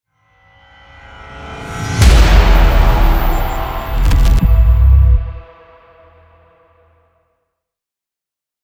shock1.wav